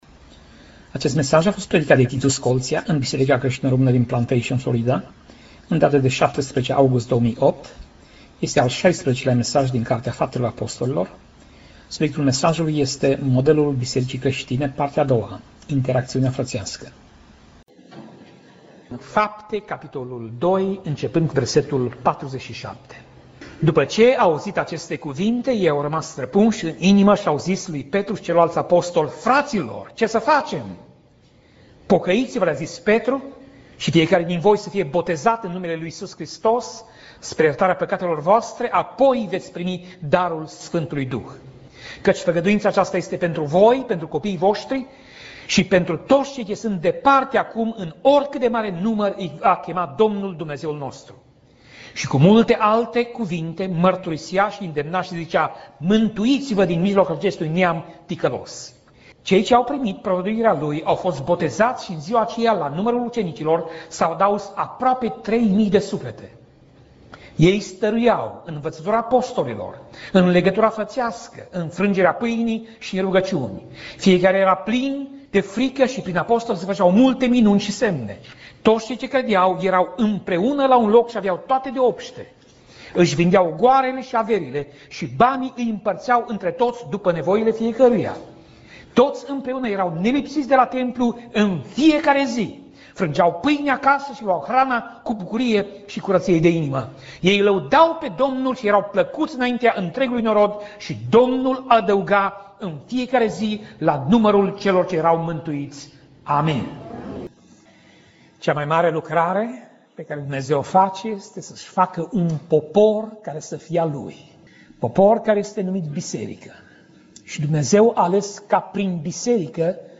Pasaj Biblie: Faptele Apostolilor 2:41 - Faptele Apostolilor 2:47 Tip Mesaj: Predica